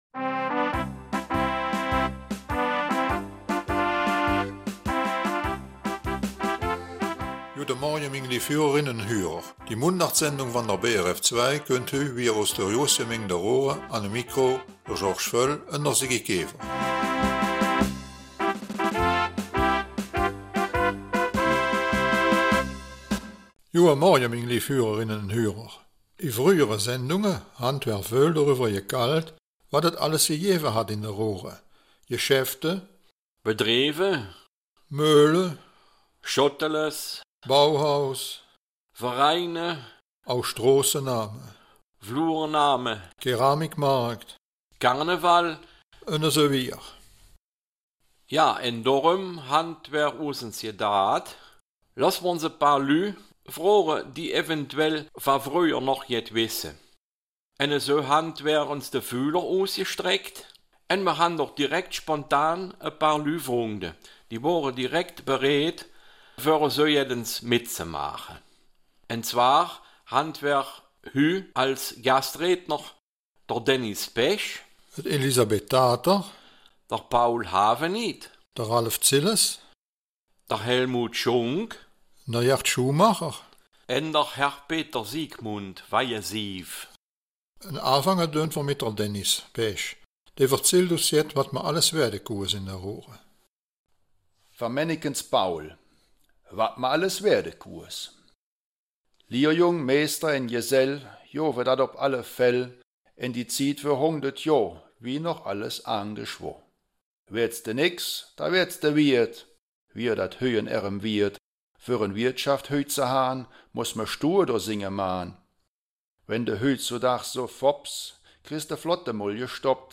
Raerener Mundart - 29. Oktober
Wir erzählen dieses Mal sehr wenig und lassen wir mal einige unserer Zuhörerinnen zu Wort kommen und Anekdoten erzählen. Folgende Gastrednerinnen erzählen aus dem Nähkästchen.